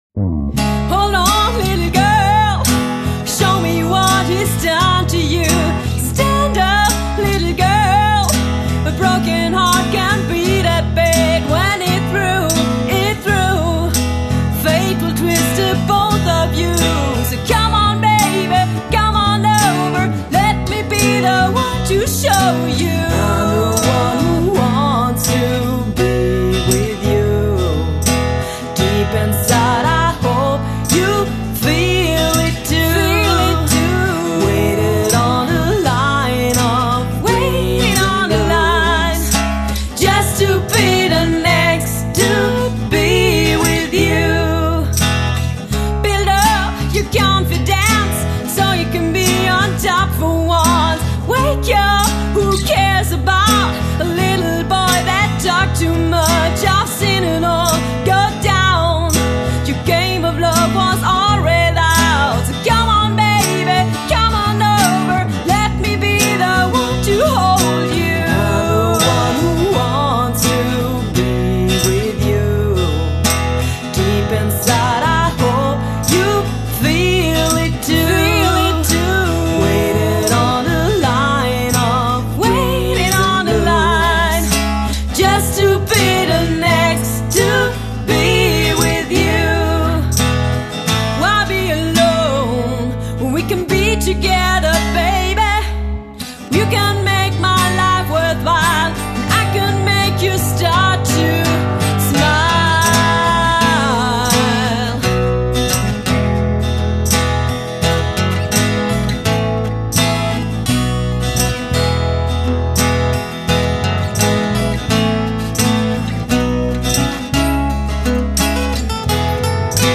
Cover-Trio aus Linz
(Cover-Genres: Pop/Classic-Rock/Oldies/Austro-/Deutsch-Pop)